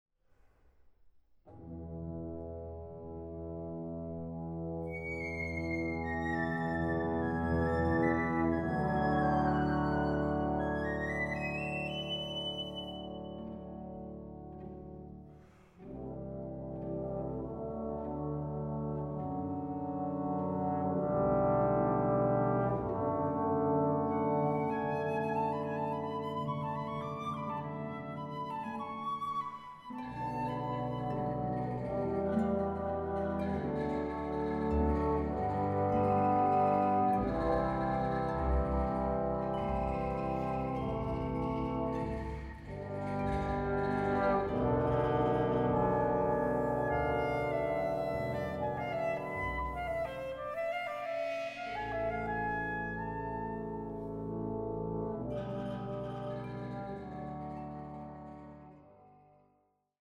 Recording: Katharina-Saal, Stadthalle Zerbst, 2025
für großes Orchester